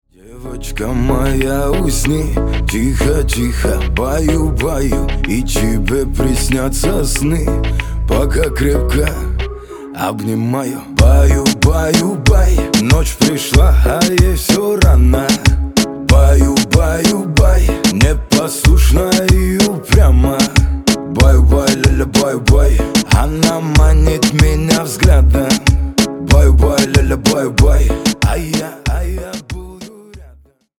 Поп Музыка
спокойные